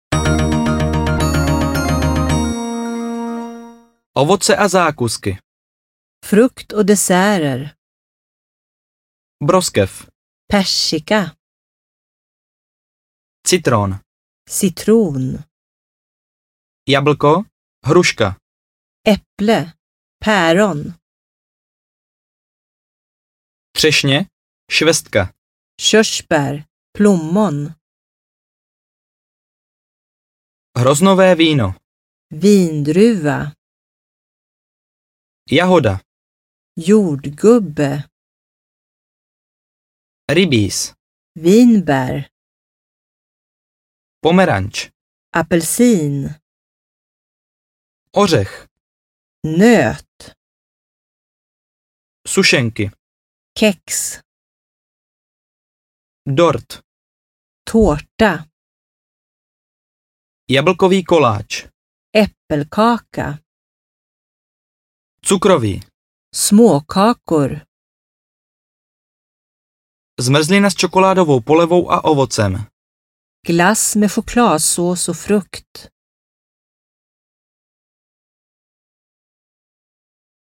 Ukázka z knihy
Obsahuje 32 témat k snadnému dorozumnění, více než 500 konverzačních obratů s výslovností, samostudium formou poslechu a opakování. Dále dvojjazyčnou nahrávku rodilých mluvčích a základní informace pro turisty.Obsah: Výslovnost Všeobecné výrazy Pozdravy a představování Otázky a všeobecné dotazy Slova opačná Hotel - ubytování Restaurace Snídaně Předkrmy Polévky Maso Ryby a plody moře Přílohy Zelenina a saláty Ovoce a zákusky Nápoje Stížnosti Cestování vlakem Cestování letadlem Cestování lodí   Cestování městskou dopravou Taxi Půjčovna aut Prohlížení pamětihodností Zábava a kultura Obchody a služby Banka Pošta Telefon Datum, čas, počasí Čísla Pohotovost